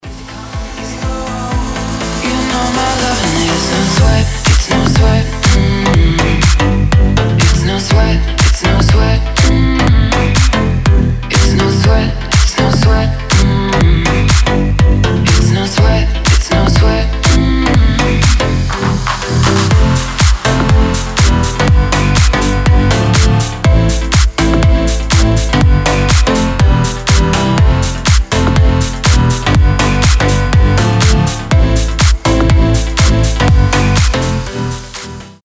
• Качество: 320, Stereo
deep house
женский голос
future house
чувственные